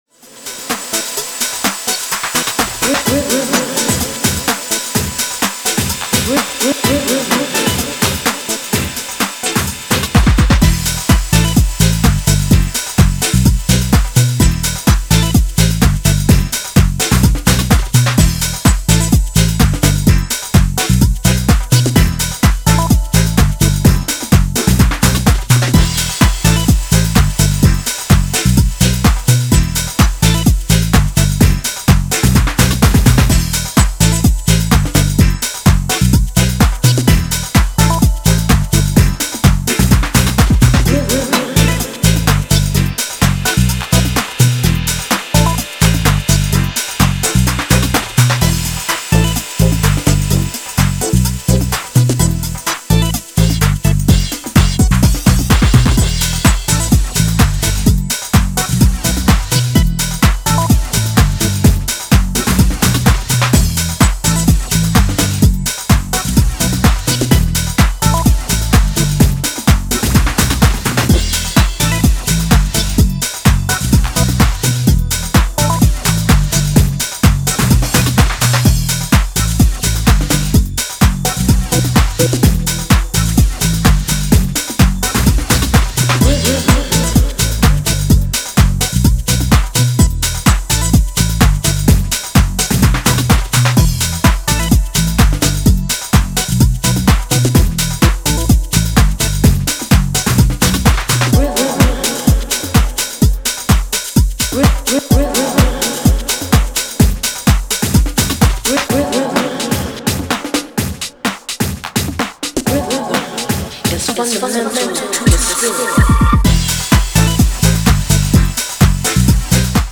跳ねたボトムとアップリフトなベースラインがフロアを牽引する